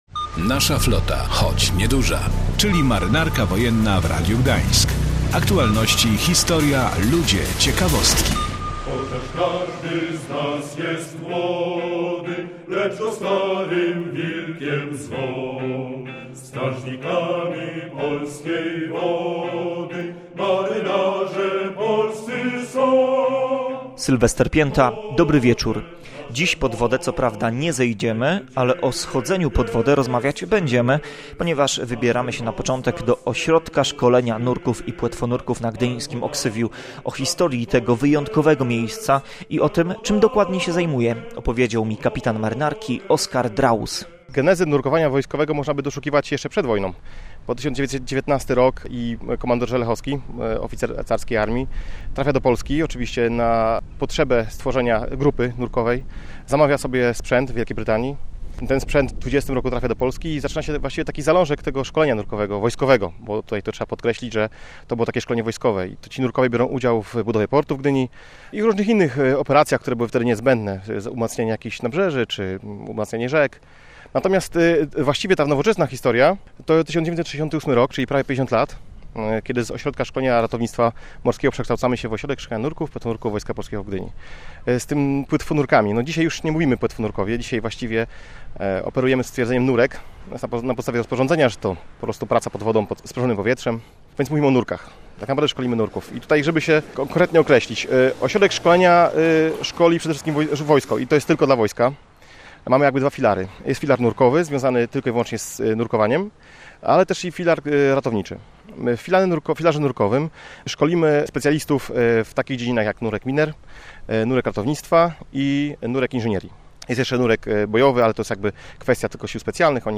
A na koniec kolejna ciekawostka z radiowego archiwum.